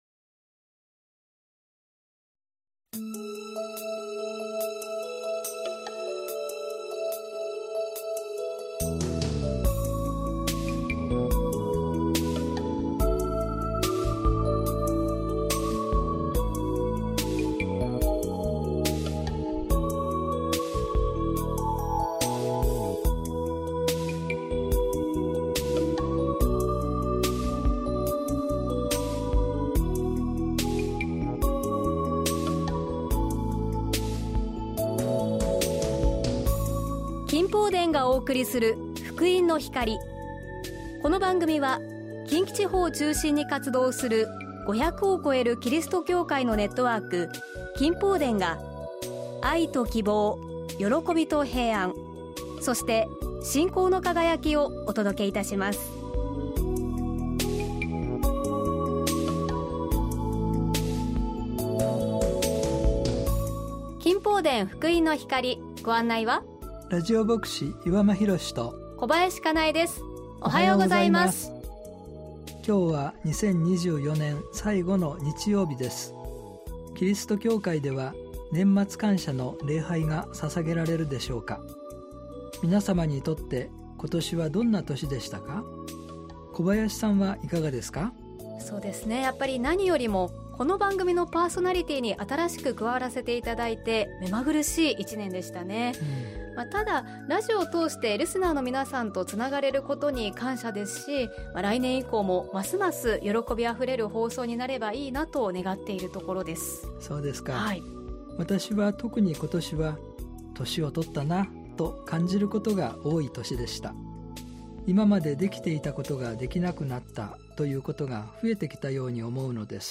年末特別番組